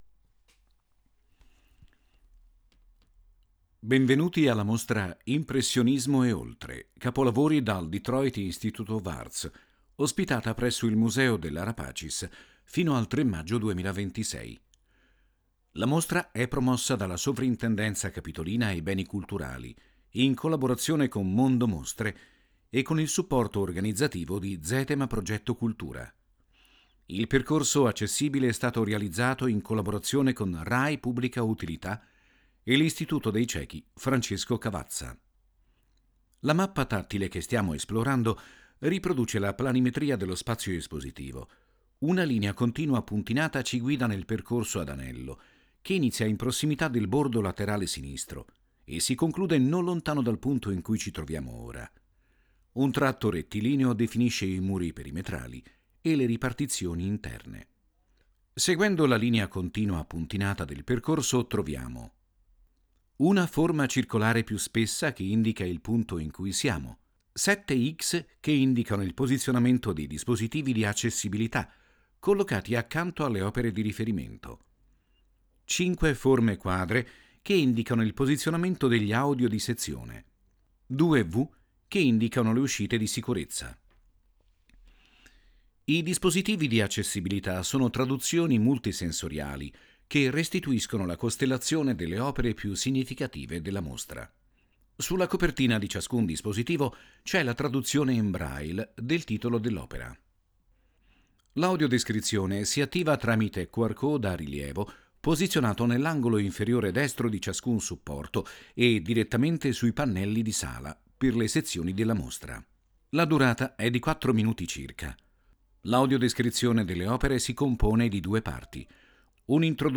• 5 AUDIODESCRIZIONI DI SEZIONE che accompagnano il visitatore nel percorso espositivo, fruibili tramite QR code